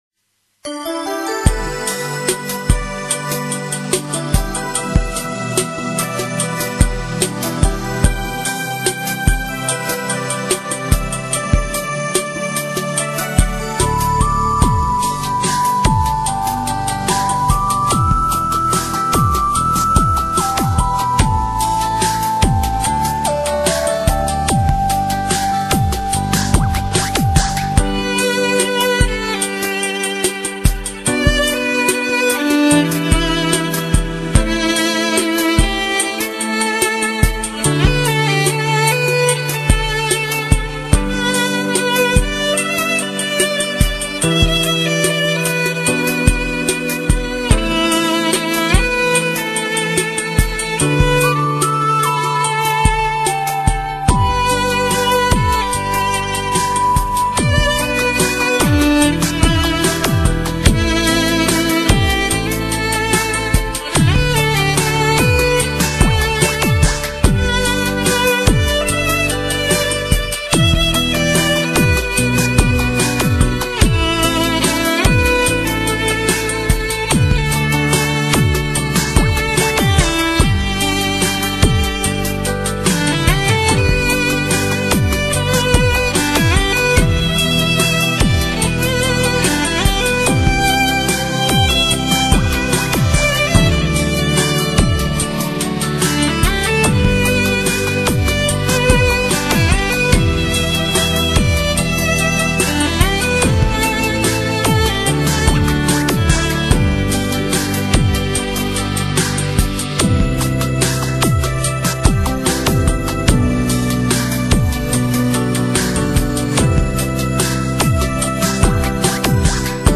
唯美、纯真气质的琴艺凝练，在如歌如泣的岁月中留下一种轻盈，等待你用潮湿的心去滋润、去珍爱。